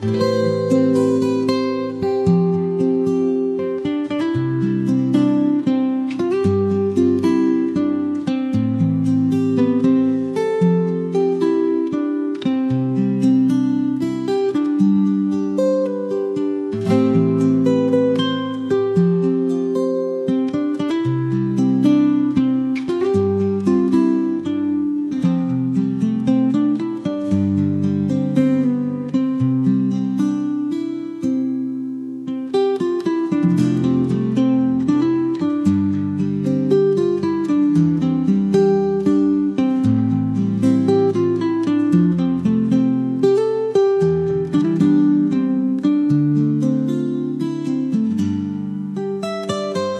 Zbiór instrumentalnych utworów akustycznych
mistrz gitary akustycznej